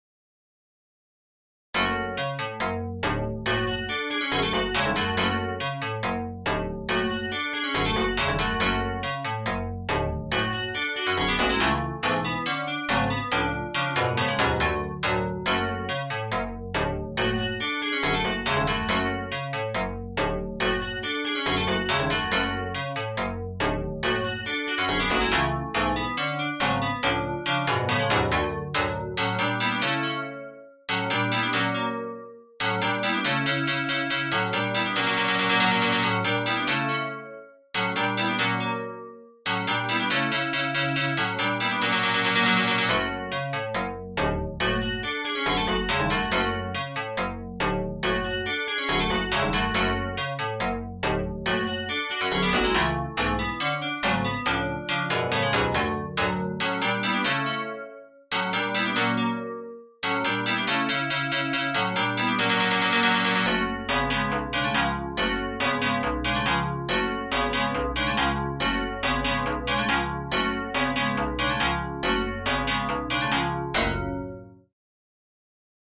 Nach einem schwedischem Volkslied.